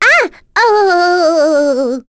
Rephy-Vox_Dead.wav